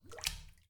water-whizlash
bath bubble burp click drain drip drop effect sound effect free sound royalty free Sound Effects